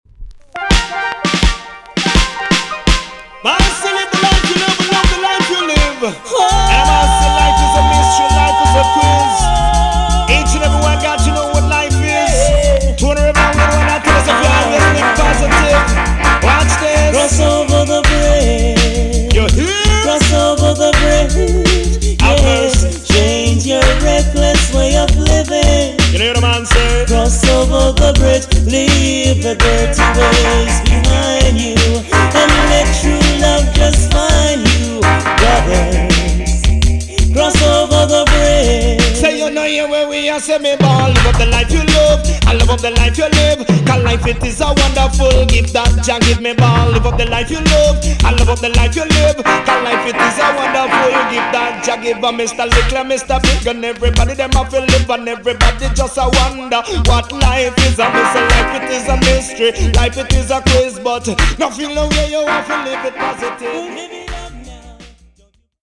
TOP >80'S 90'S DANCEHALL
EX 音はキレイです。